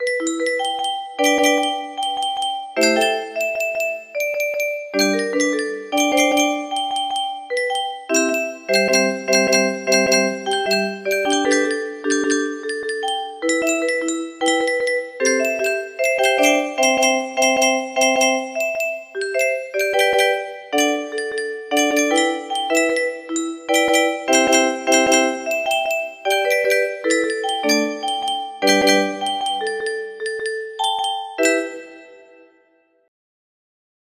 Unknown Artist - Untitled music box melody
Imported from MIDI from imported midi file (6).mid